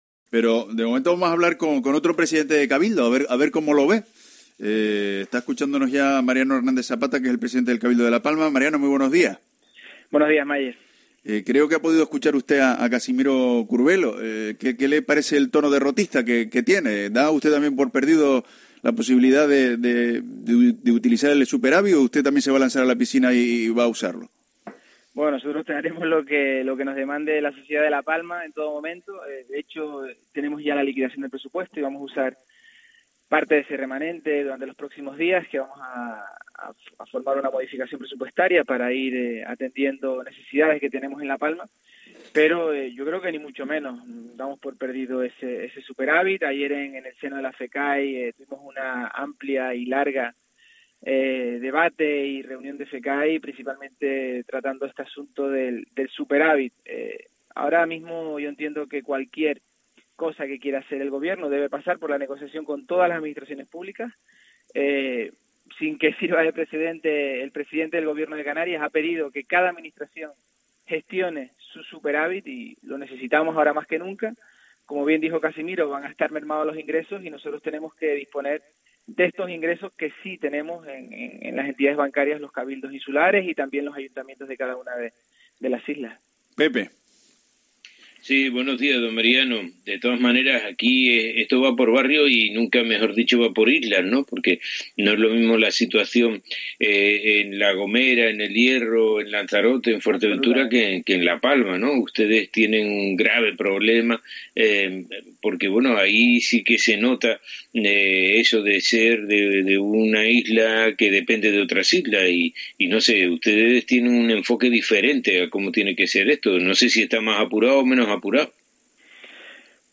Entrevista a Mariano Hernández Zapata, presidente del Cabildo de La Palma, en La Mañana en Canarias